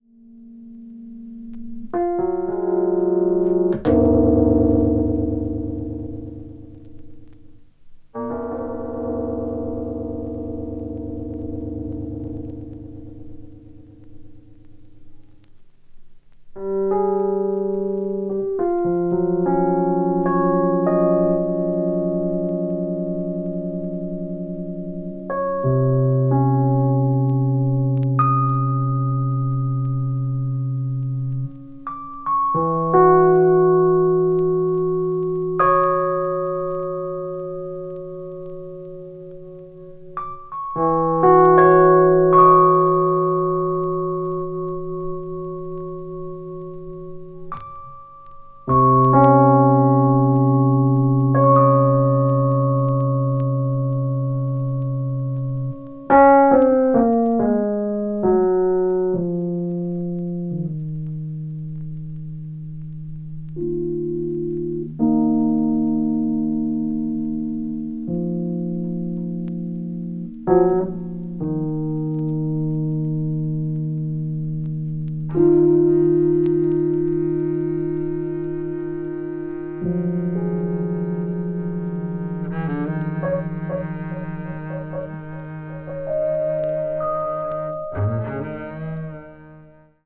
avant-jazz   contemporary jazz   deep jazz   free jazz